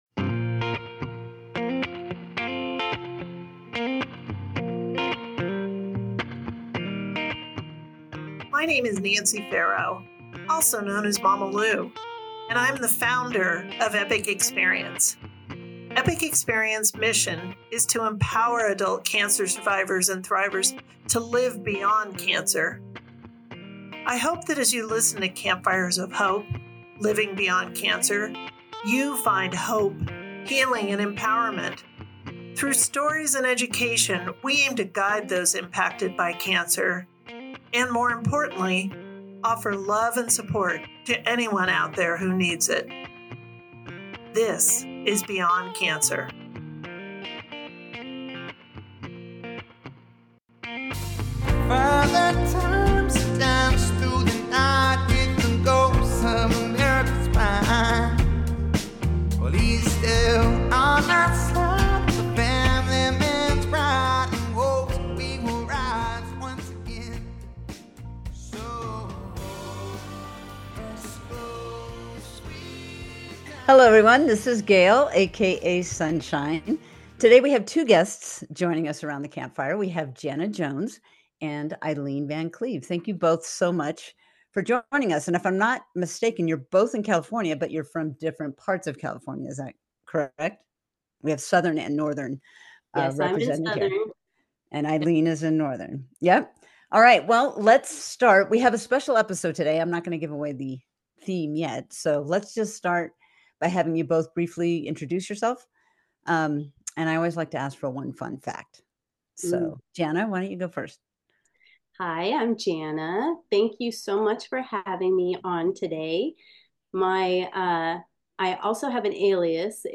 This candid conversation is a powerful reminder that stepping back is not a weakness—it’s a sometimes necessary act of strength.